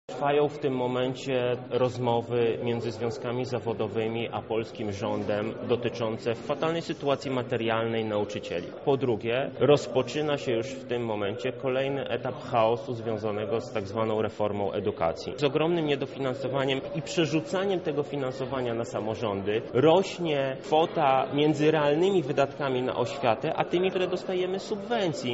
— mówi Michał Krawczyk, radny miasta Lublin